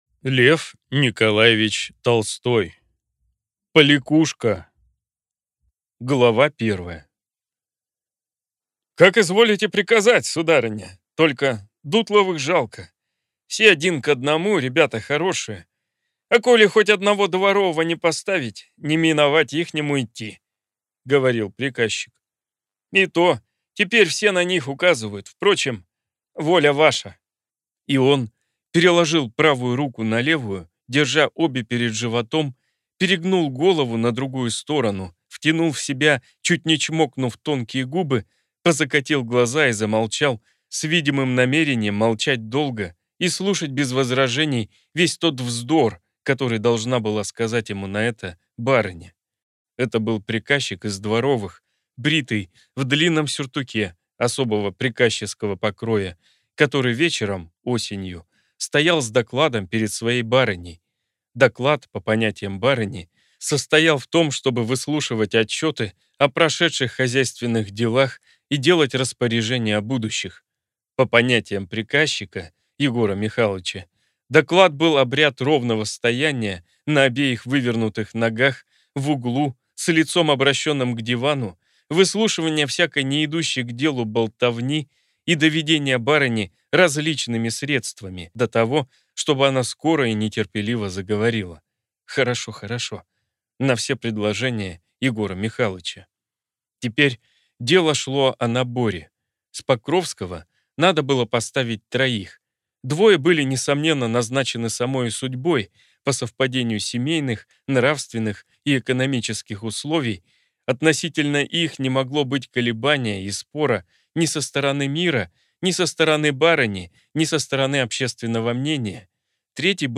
Аудиокнига Поликушка | Библиотека аудиокниг